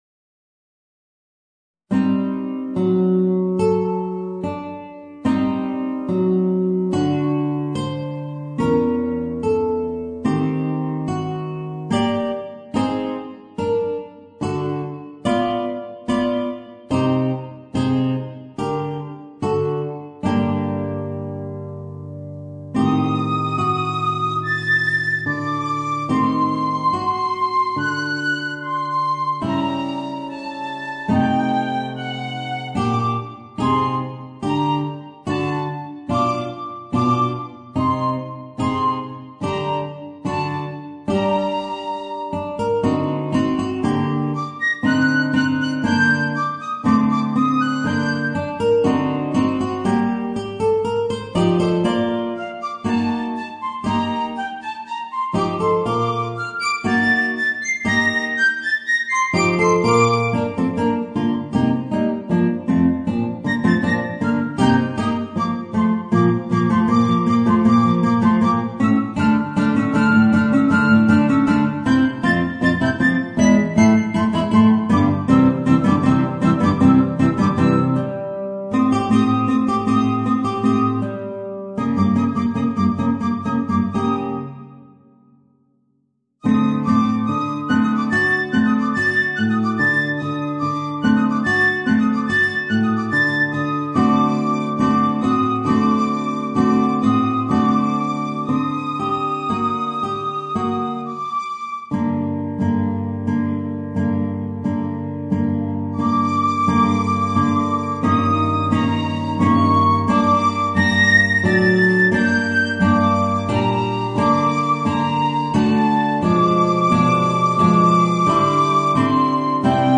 Voicing: Guitar and Piccolo